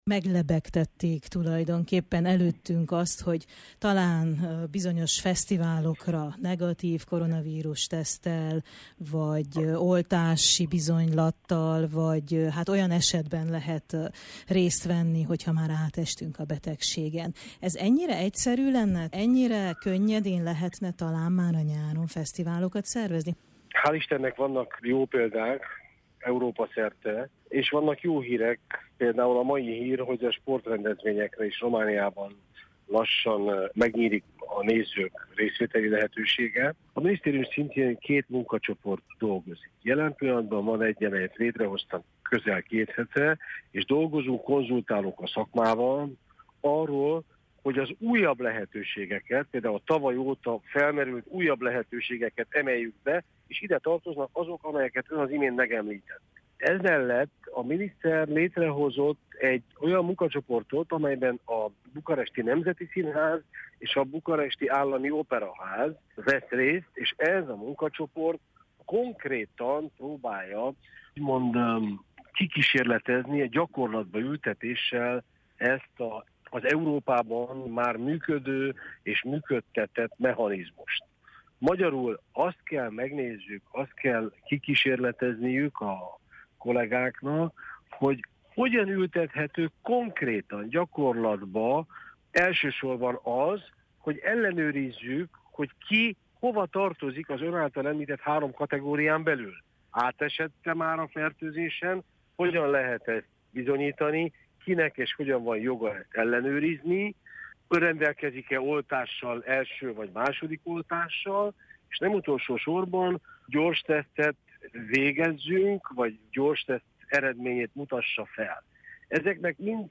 Hogyan működhetne mindez? – kérdeztük Demeter András művelődésügyi államtitkártól.